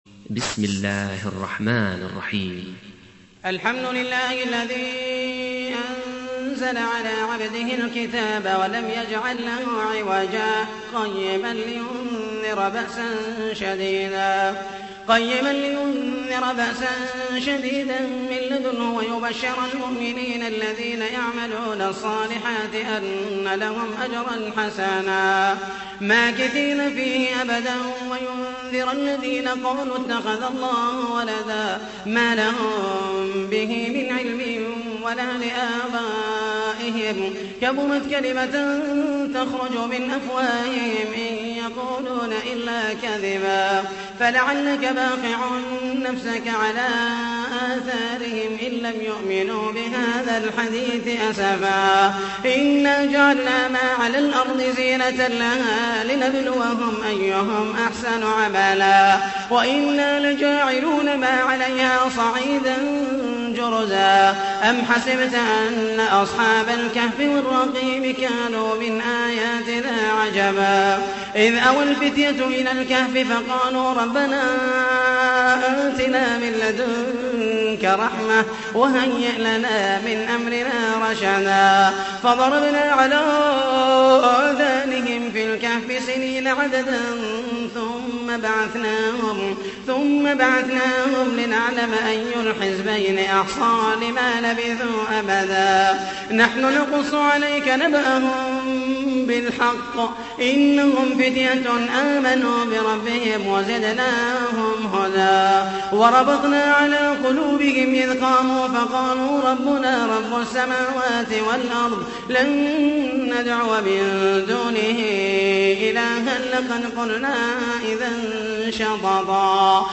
تحميل : 18. سورة الكهف / القارئ محمد المحيسني / القرآن الكريم / موقع يا حسين